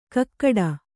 ♪ kakkaḍa